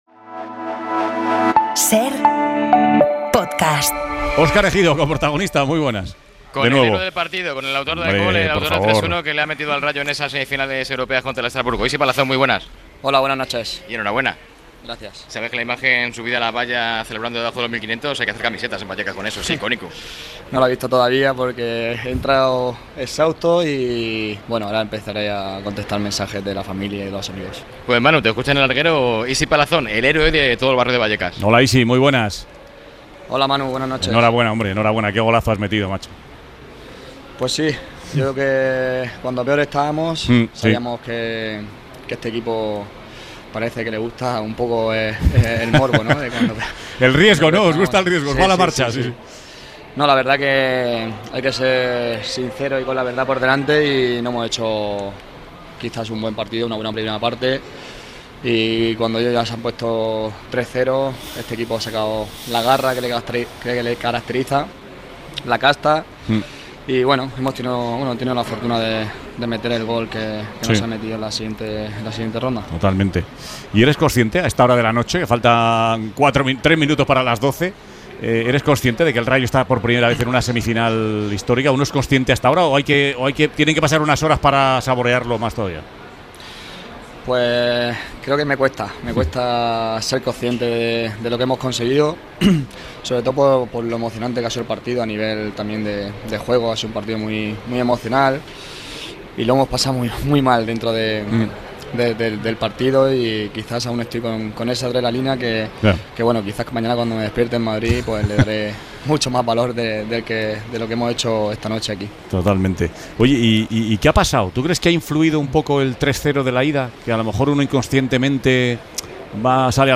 El capitán del equipo rayista habló en 'El Larguero' tras pasar a semifinales de la Conference League